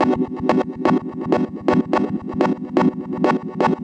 cch_synth_loop_pulse_125_Em.wav